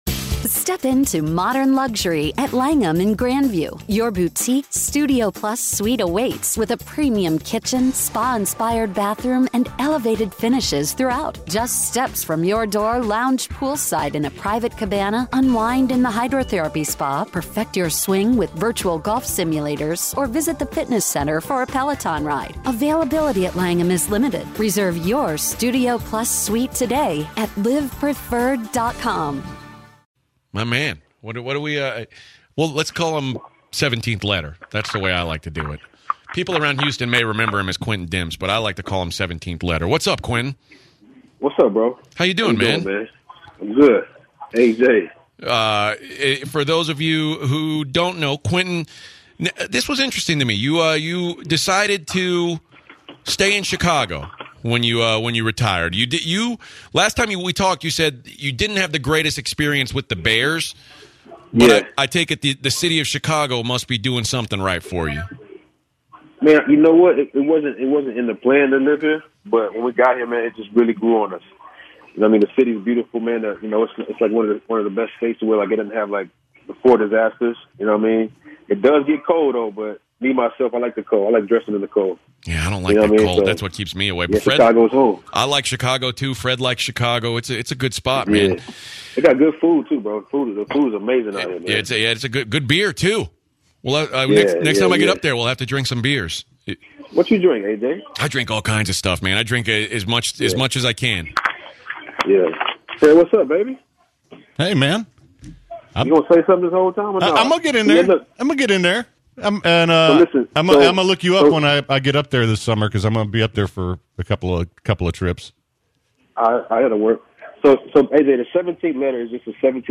Former NFL Safety joins The Blitz to discuss life after football, living in Chicago, his new name, and his love and focus in his music career.